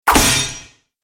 دانلود آهنگ دعوا 46 از افکت صوتی انسان و موجودات زنده
دانلود صدای دعوا 46 از ساعد نیوز با لینک مستقیم و کیفیت بالا
جلوه های صوتی